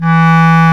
WND BSCLARE3.wav